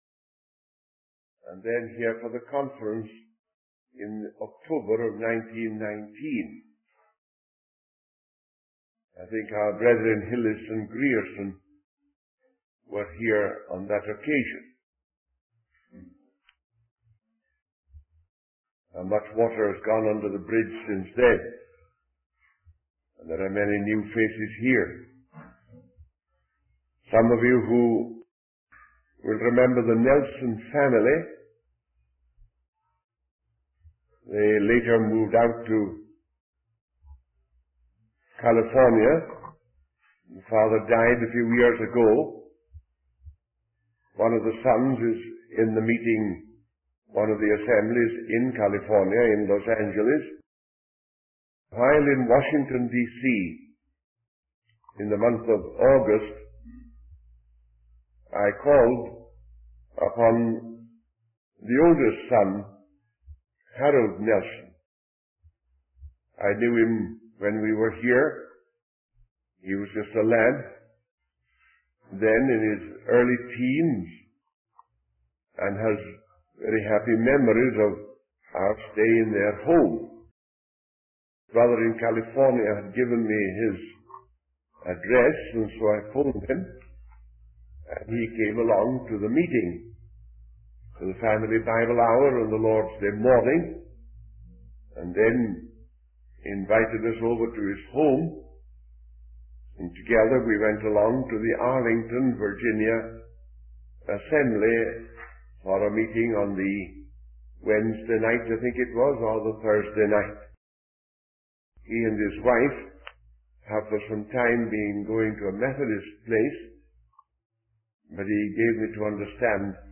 In this sermon, the speaker begins by expressing his desire to serve the Lord and preach the Word of God.